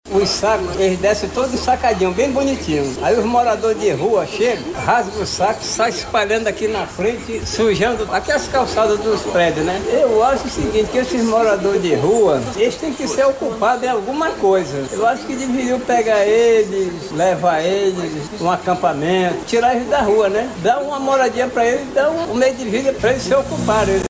Para o agente de portaria